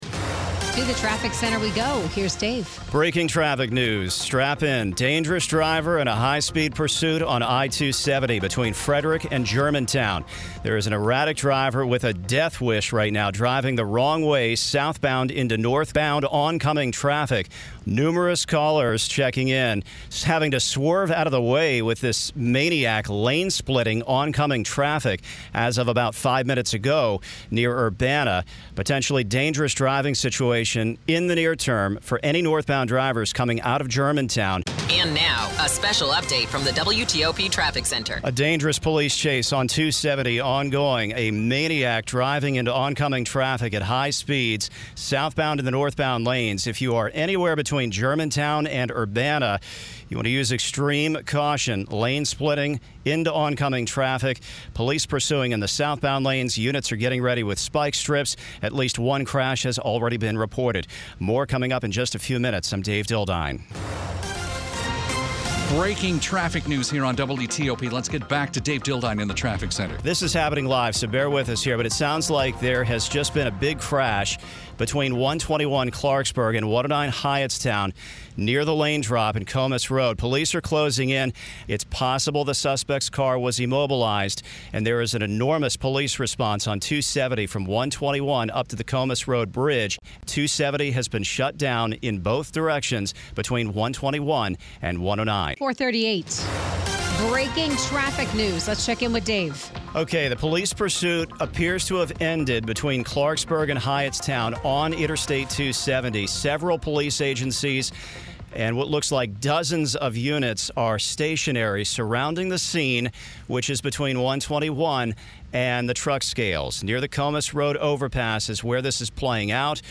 Traffic Reporter